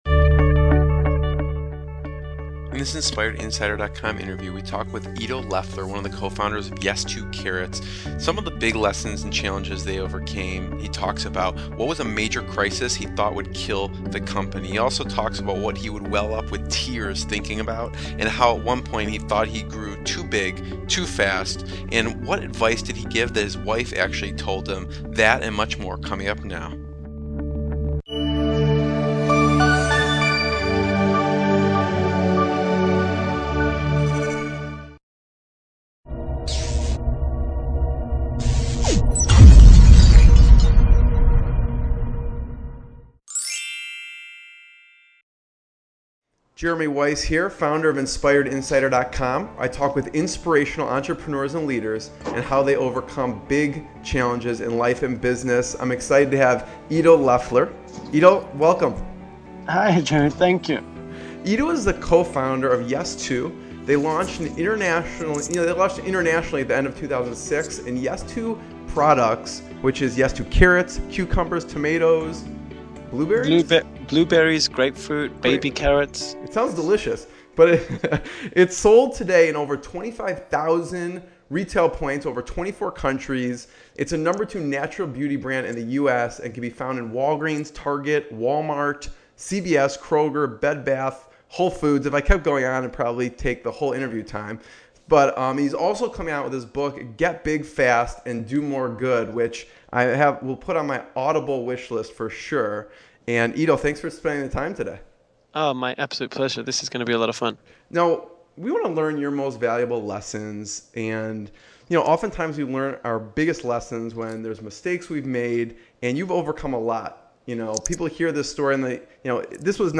Inspirational Business Interviews with Successful Entrepreneurs and Founders